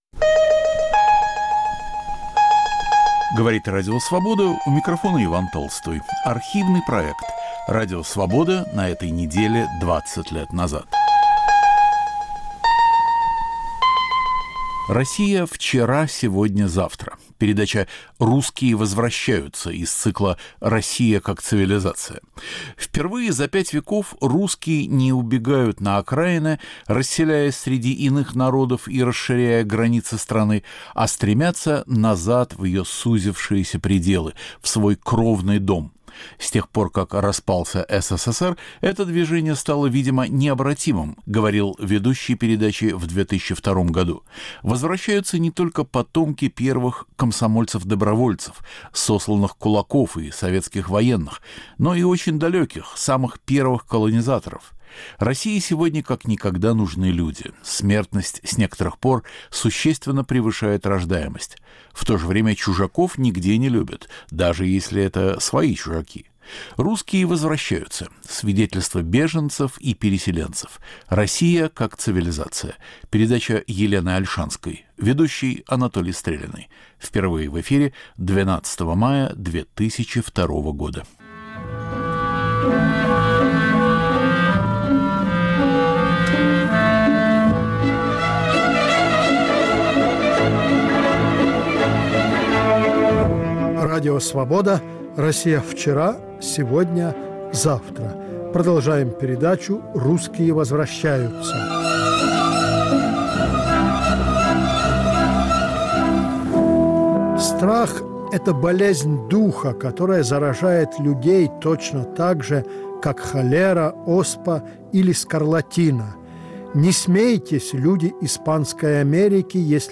Ведущий Анатолий Стреляный.